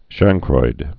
(shăngkroid)